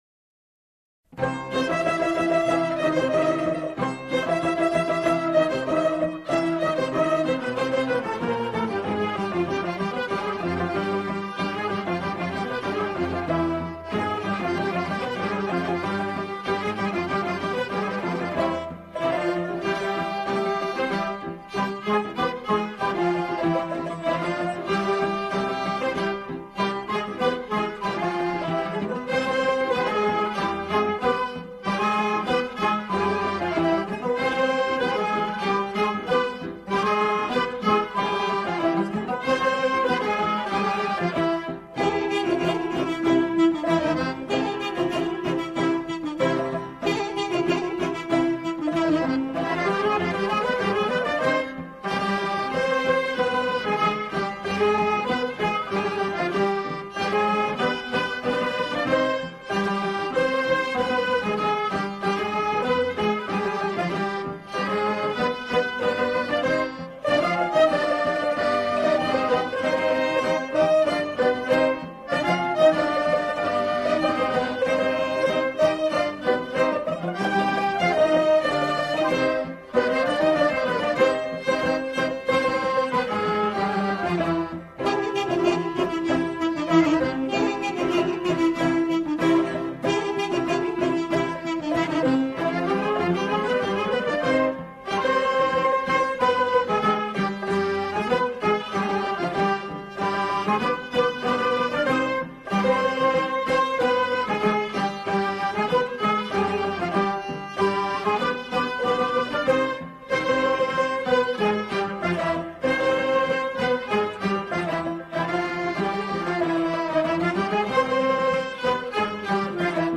سرود نوستالژی